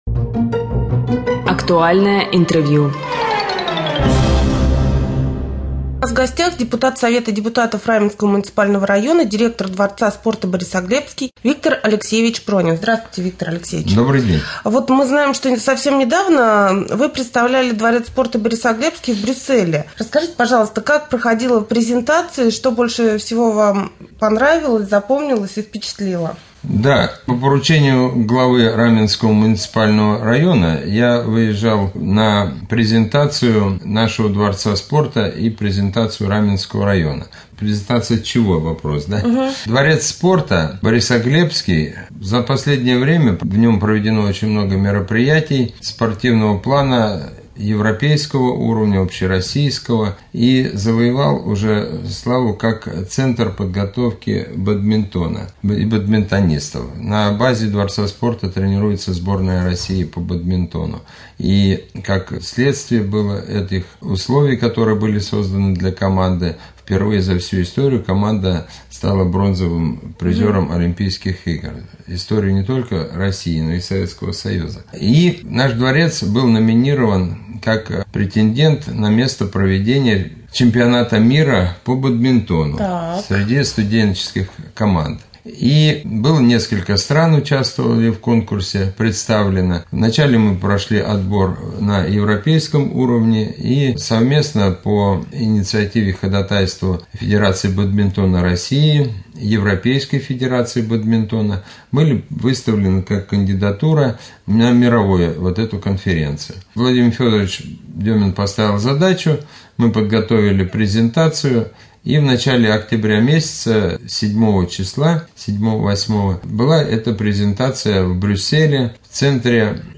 2.Рубрика «Актуальное интервью ». Гость студии депутат совета депутатов Раменского муниципального района, директор дворца спорта «Борисоглебский» Виктор Алексеевич Пронин.